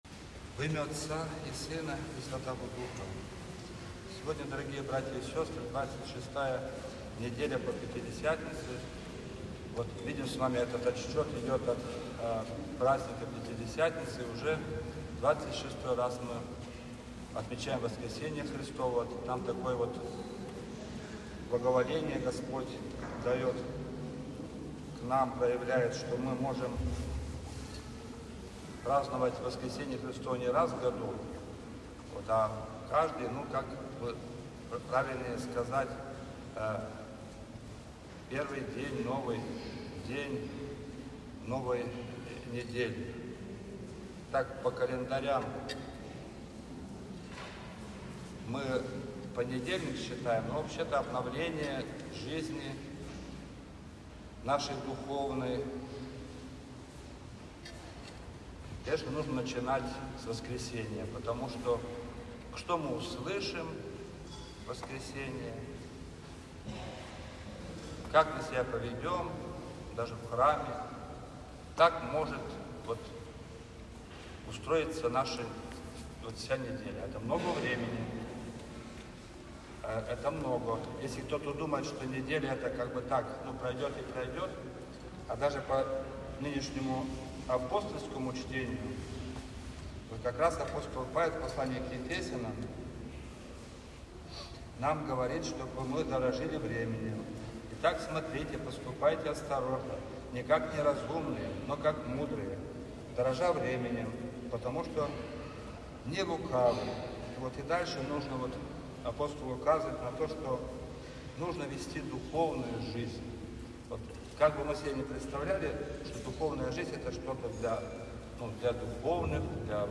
Божественная-литургия.mp3